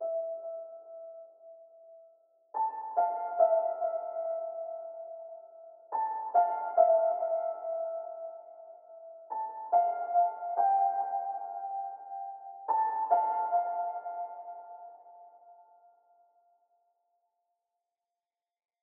AV_Corridor_Piano_C#min_142BPM
AV_Corridor_Piano_Cmin_142BPM.wav